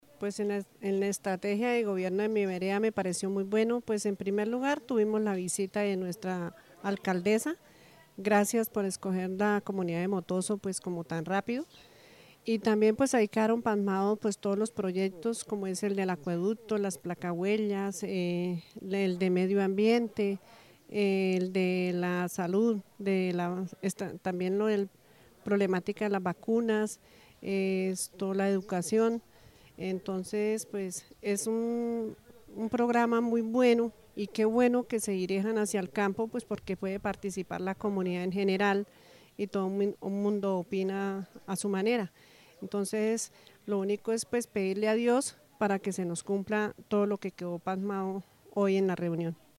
Habitante Vereda San José de Motoso.mp3